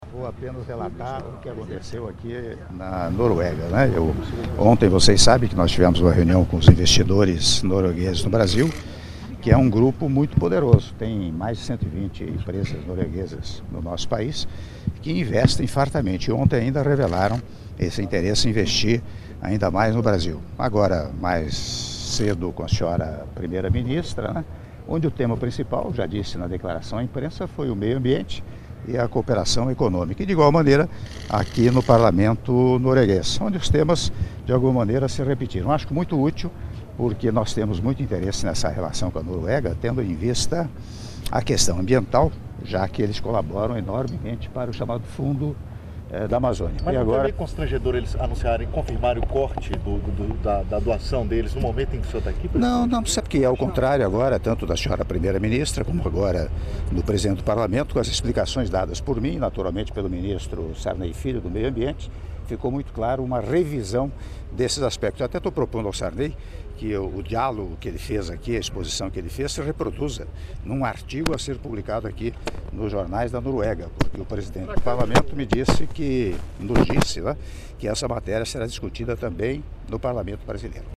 Áudio da entrevista coletiva concedida pelo Presidente da República, Michel Temer, após encontro com o Presidente do Parlamento da Noruega, Olemic Thommessen - (01min21s) - Oslo/Noruega